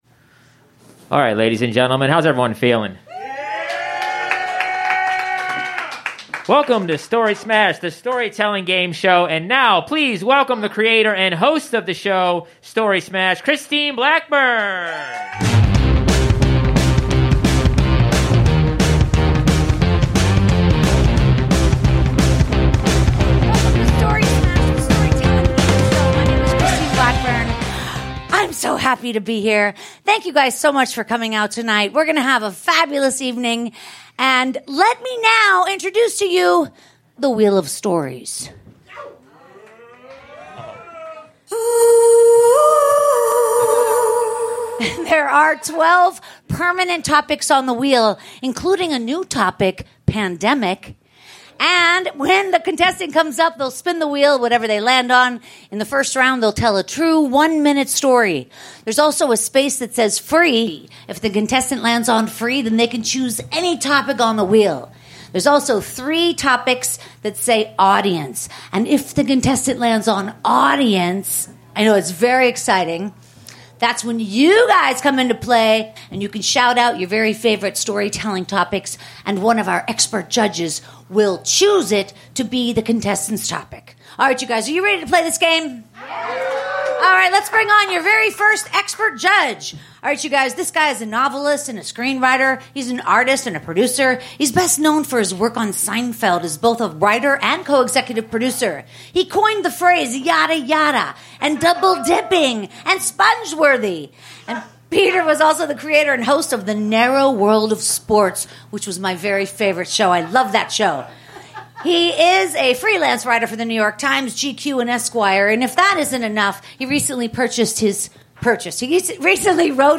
LIVE at the Hollywood Improv in September 2021.